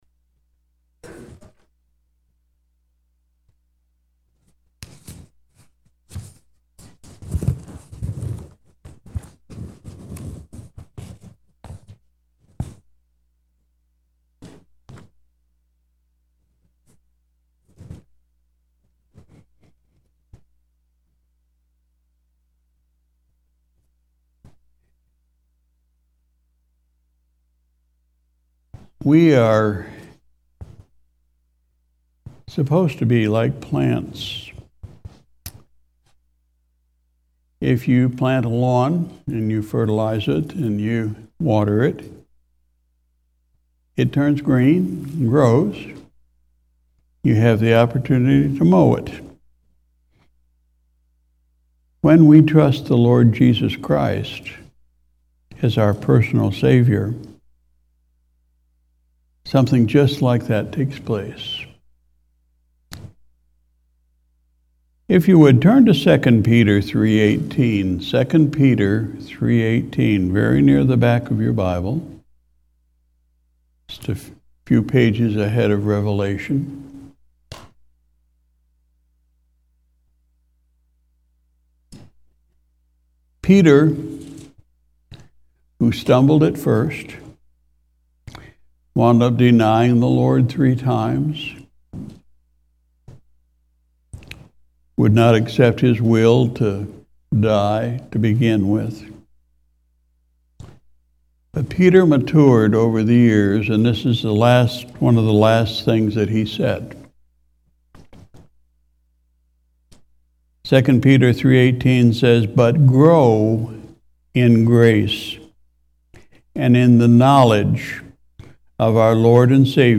August 20, 2023 Sunday Morning Service Pastor’s Message: Growing up in the Lord